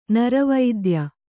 ♪ nara vaidya